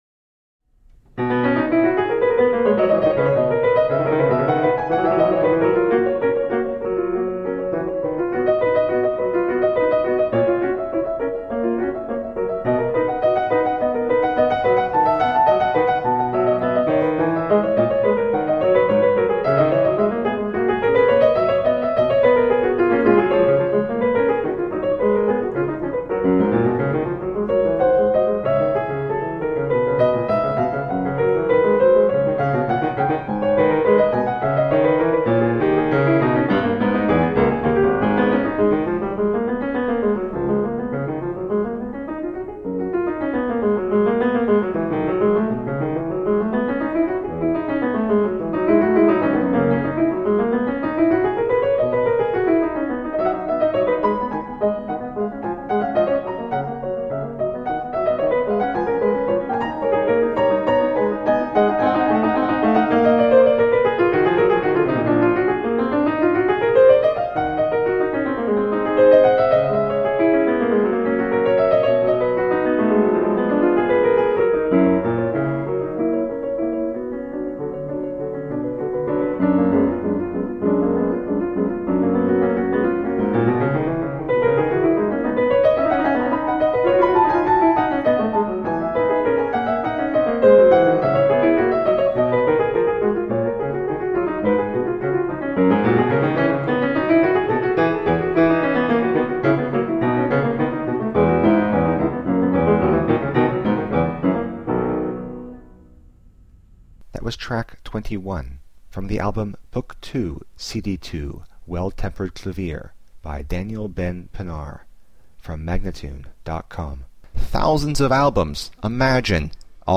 solo piano music
Classical, Baroque, Instrumental Classical, Classical Piano